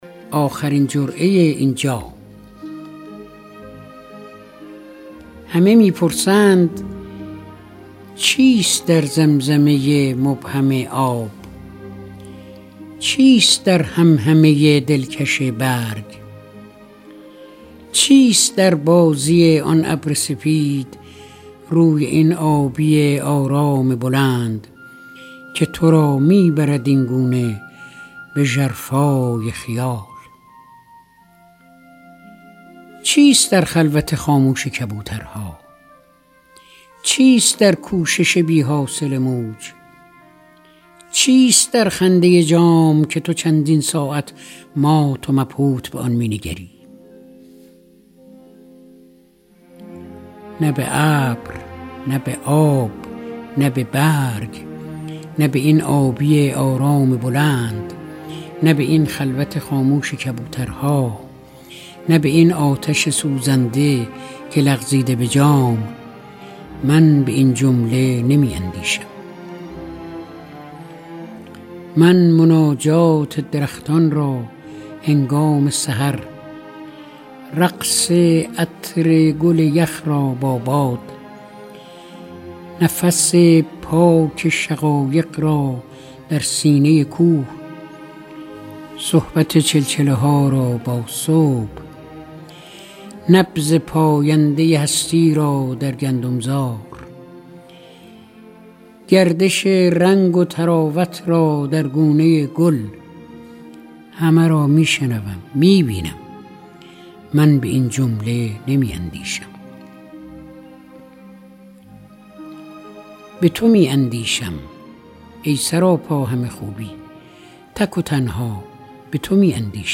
دانلود دکلمه آخرین جرعه این جام با صدای فریدون مشیری